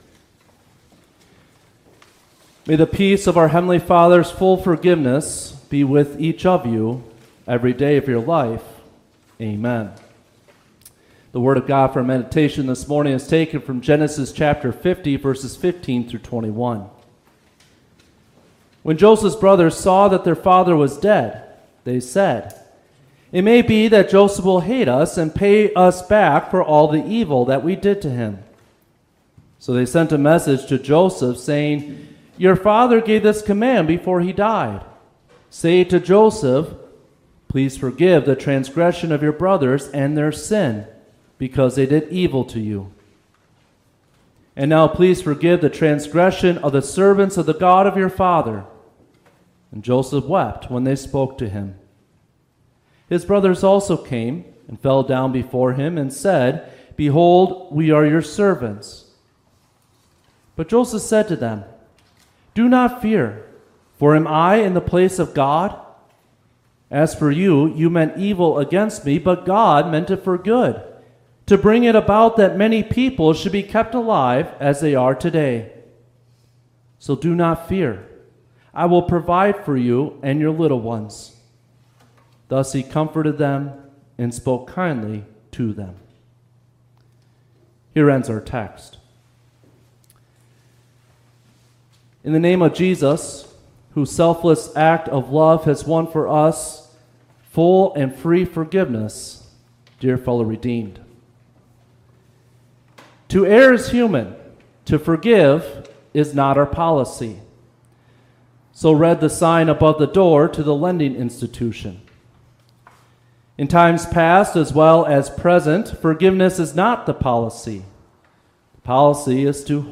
Fourth-Sunday-after-Trinity.mp3